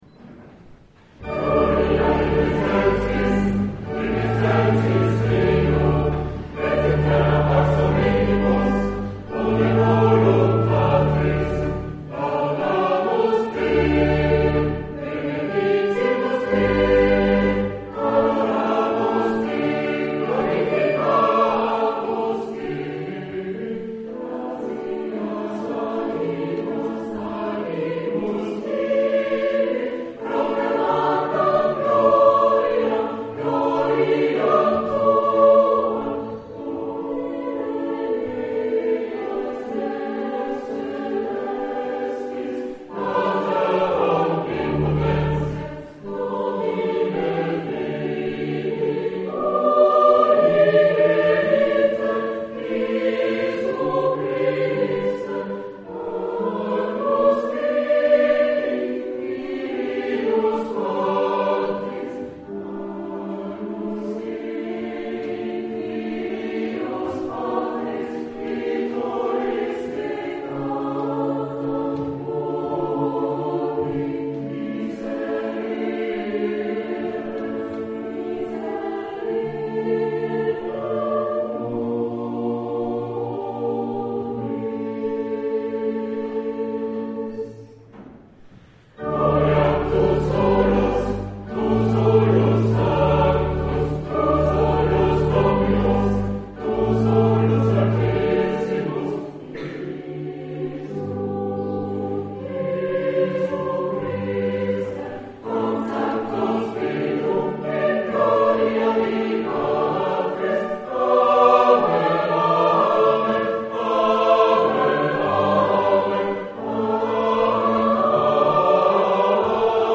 Hochamt - Ostersonntag 2009